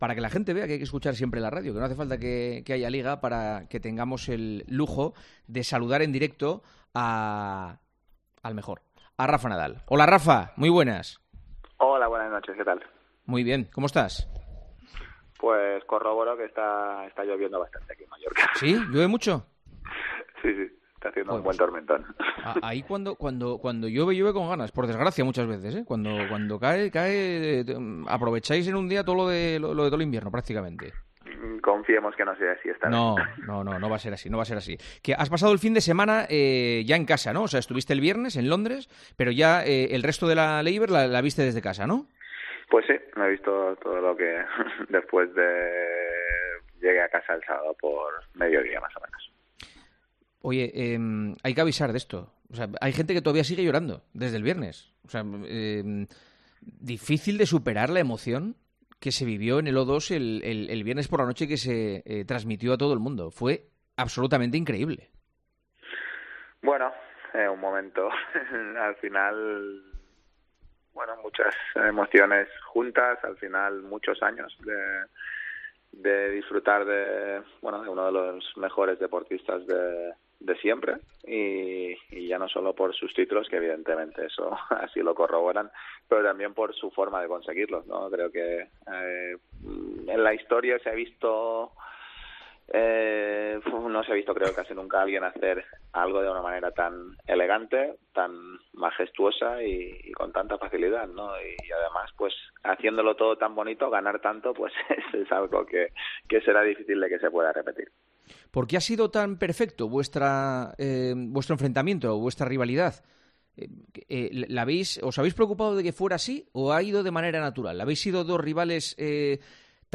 Rafa Nadal fue el gran protagonista de El Tertulión de Tiempo de Juego de este domingo, con Juanma Castaño , tan solo dos días después de que diera la vuelta al mundo la imagen de un Nadal roto en lágrimas cogiendo de la mano a su amigo Roger Federer, justo tras poner el punto y final a su carrera como tenista .